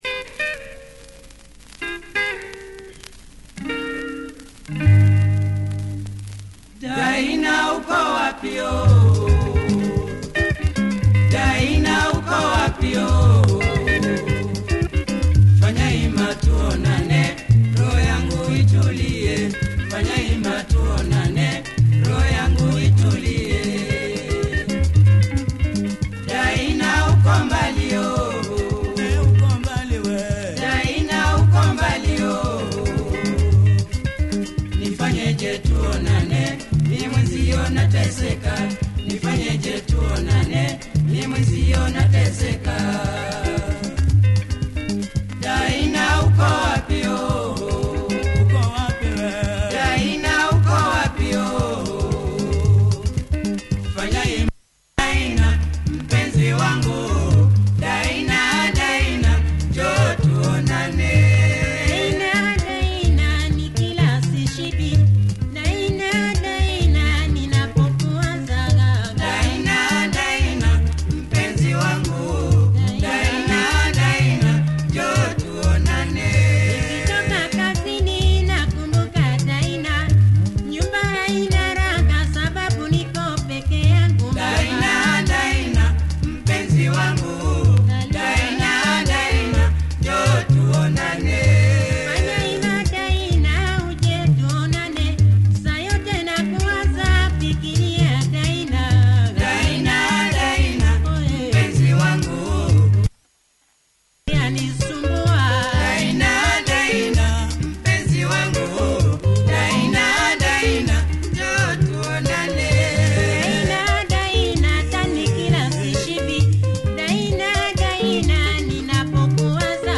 Lovely Cavacha styled double part two sider